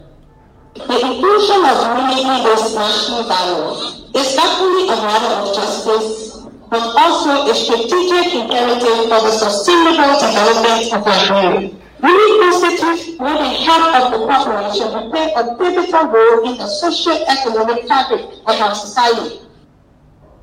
The Gender Minister made the claim in an ELBC news report, highlighting the importance of women’s inclusion in development programs at the Women of Liberia town hall meeting with President Joseph Boakai.